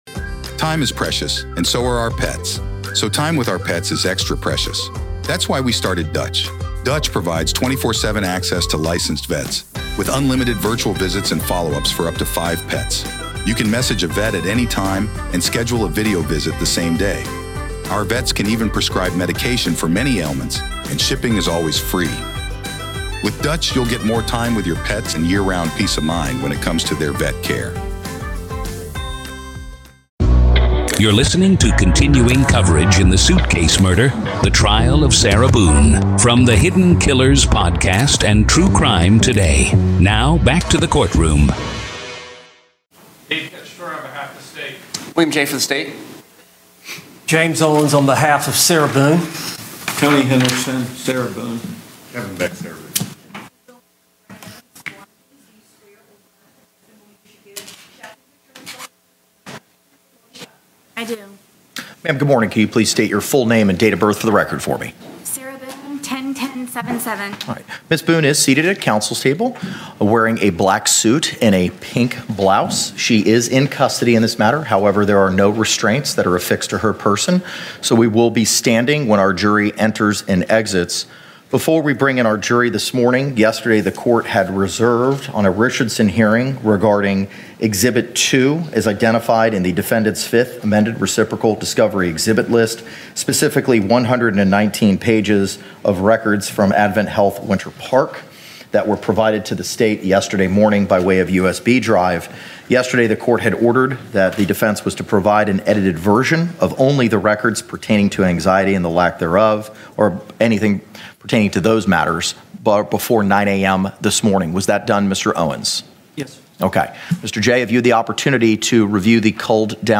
DAY 1 PART 1 : Opening Statements